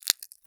High Quality Footsteps
STEPS Glass, Walk 03.wav